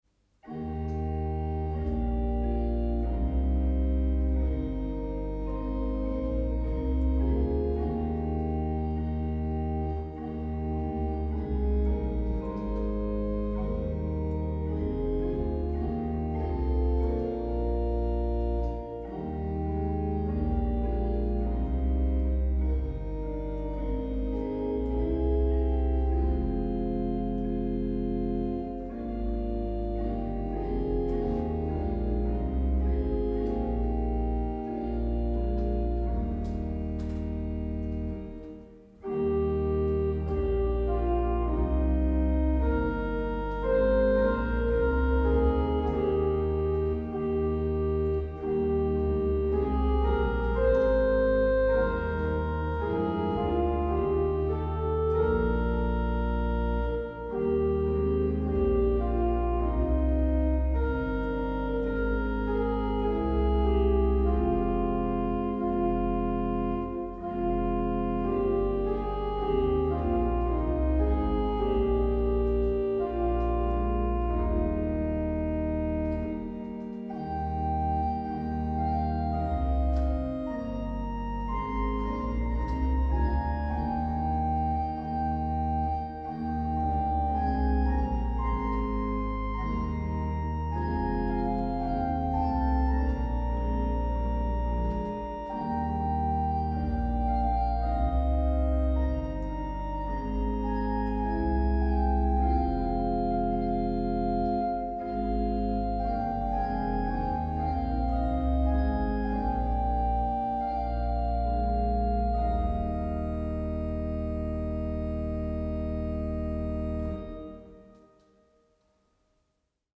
🙂 Die Improvisationen sind allerdings komplett ungeschnitten.
5 kleine Choralimprovisationen, eingespielt im Frühjahr 2020 an der Markkleeberger Ahlborn-Orgel: